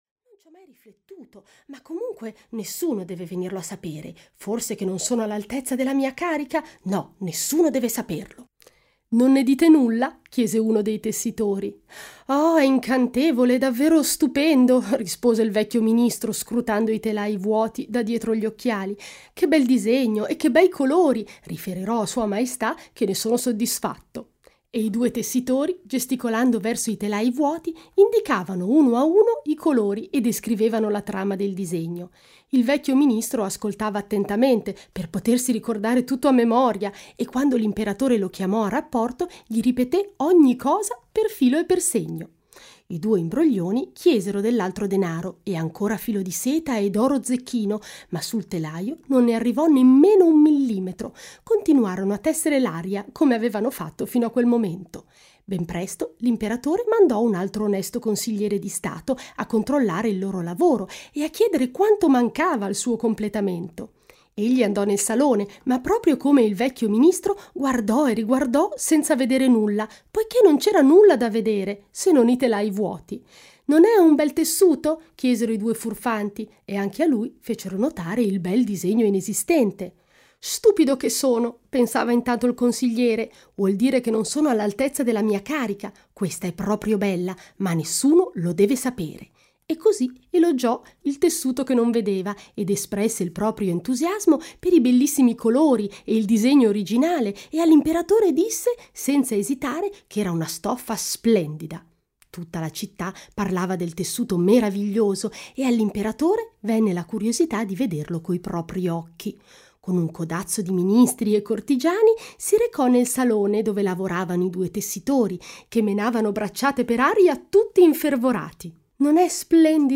"Le fiabe più belle" di AA.VV. - Audiolibro digitale - AUDIOLIBRI LIQUIDI - Il Libraio
• Letto da: Benedetta Parodi